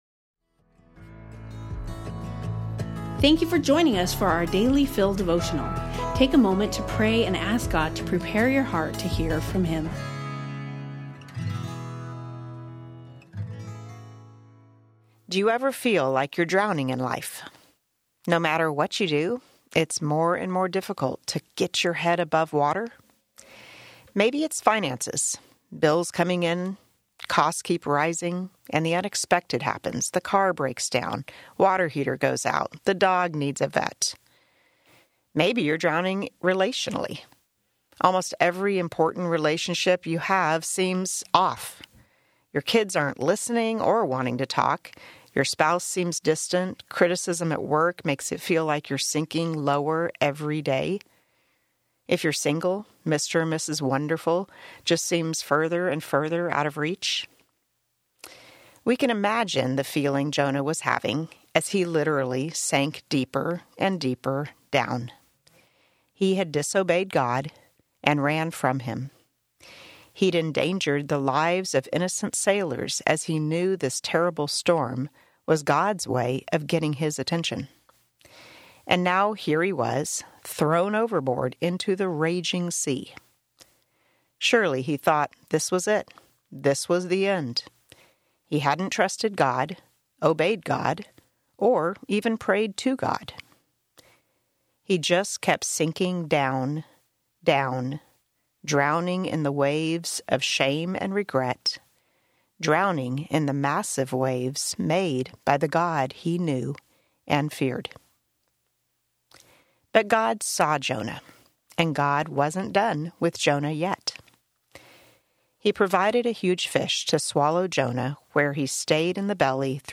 We hope you will enjoy these audio devotionals.